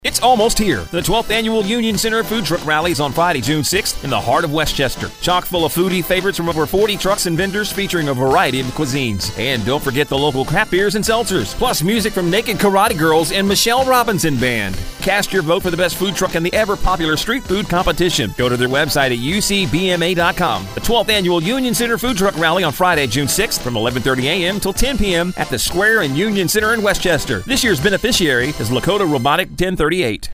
wmoh-ftr-commercial-2025.mp3